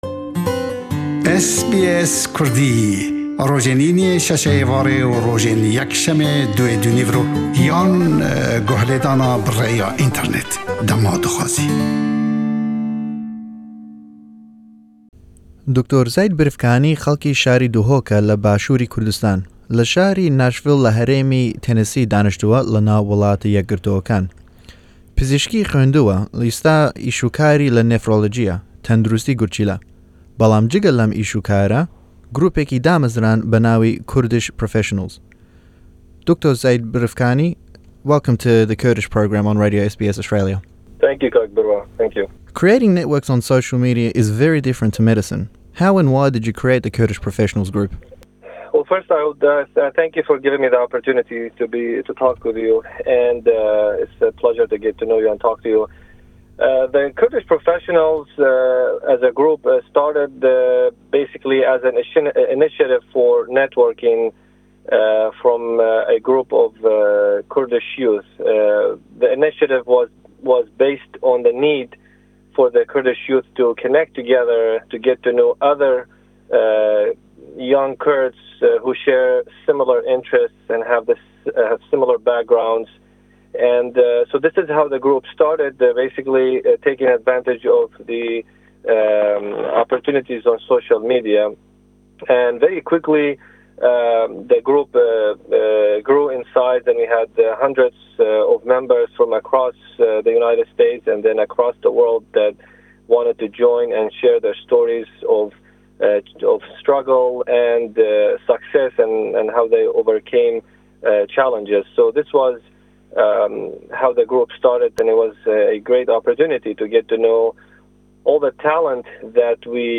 Lem lêdwane